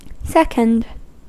Ääntäminen
Ääntäminen US : IPA : /ˈsɛk.ənd/ Haettu sana löytyi näillä lähdekielillä: englanti Käännös Adjektiivit 1. 2o {m} 2. 2º {m} 3. 2a {f} 4. 2ª {f} 2nd on sanan second lyhenne.